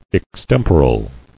[ex·tem·po·ral]